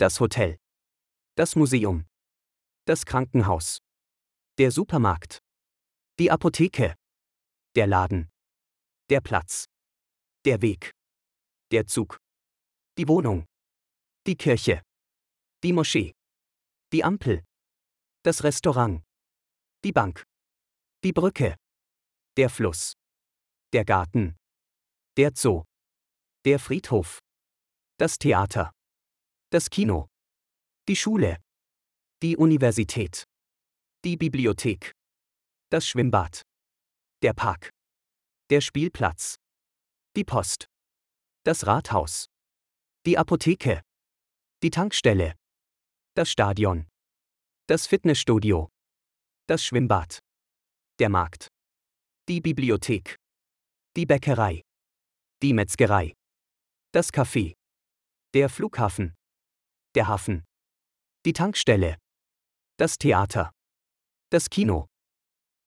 سنضع لكم لفظ كل كلمة مكتوبة بالعربي وايضاً صوتياً لأن كلمات المانية مكتوبة بالعربي تساعد المبتدئين في تعلم اللغة الألمانية بشكل أفضل وأسرع.